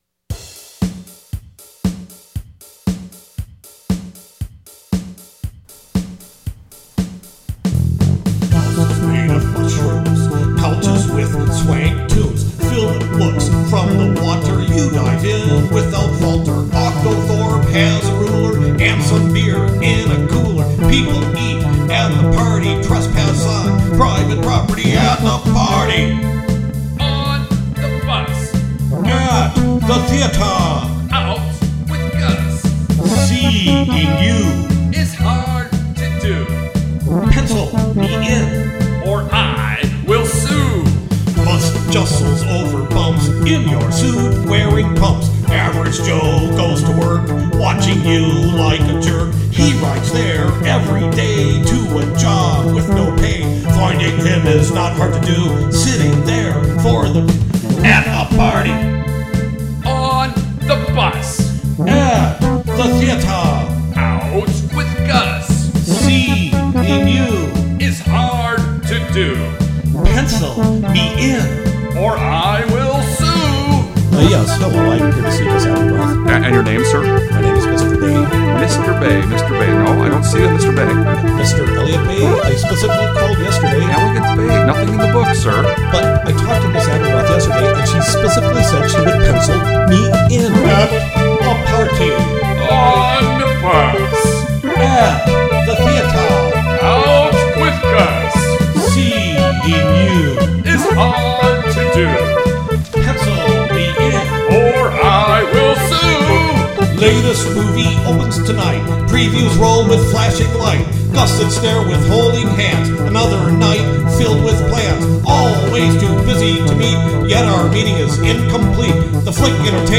Nice groove!
Pros Cant get much more 80's than this.
The Handclaps wereKinda a cop out